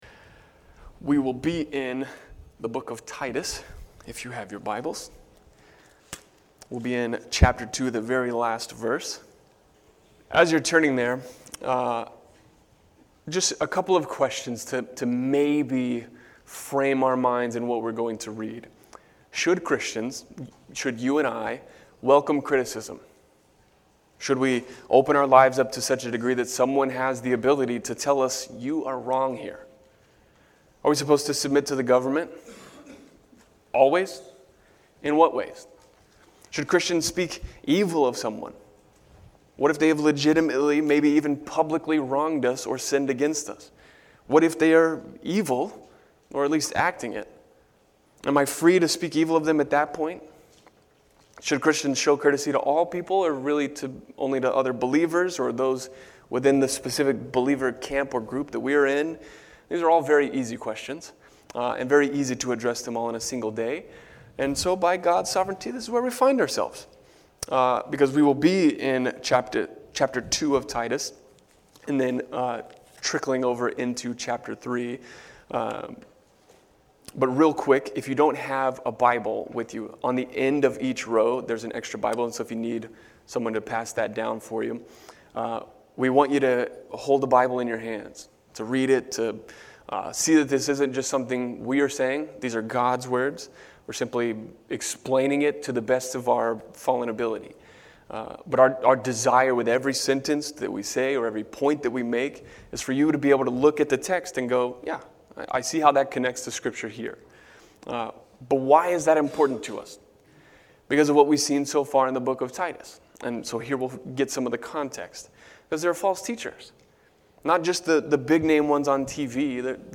Sermons | Grace Reformed Baptist Church of North Texas